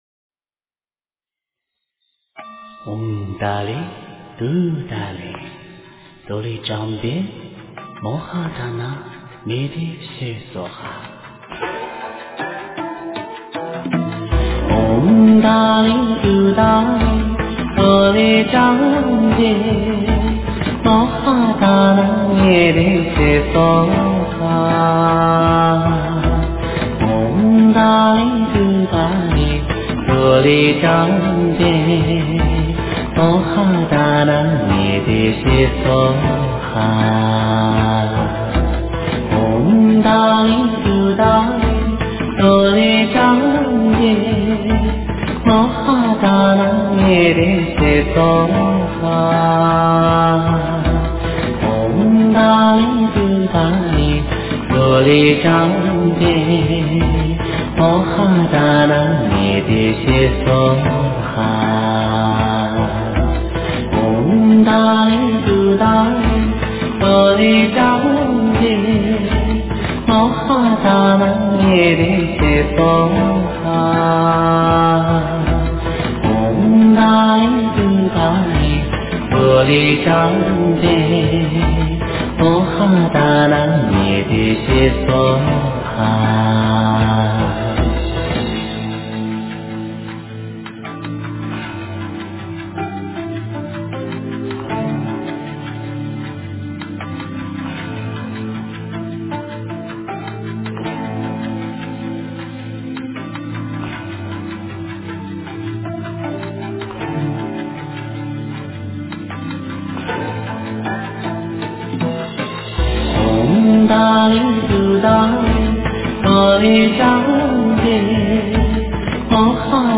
佛音 真言 佛教音乐 返回列表 上一篇： 楞严咒(快诵